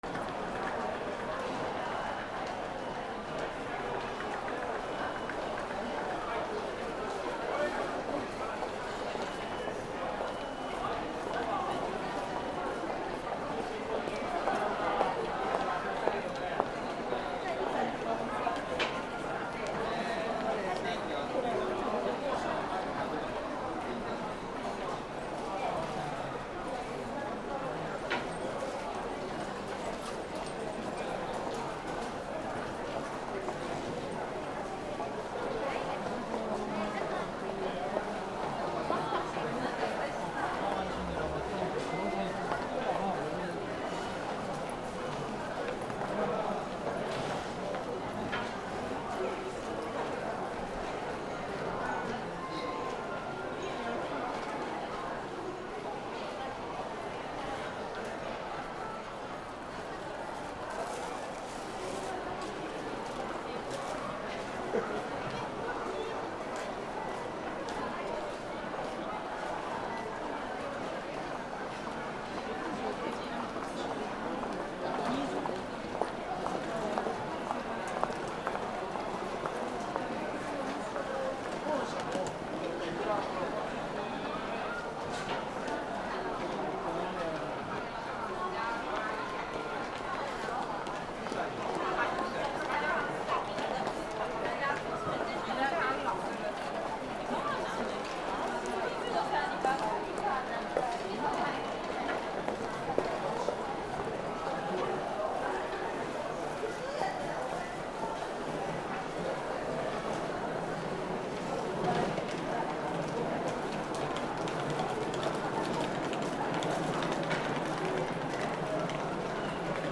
地下鉄難波駅周辺地下通路 雑踏 足音など
/ E｜乗り物 / E-60 ｜電車・駅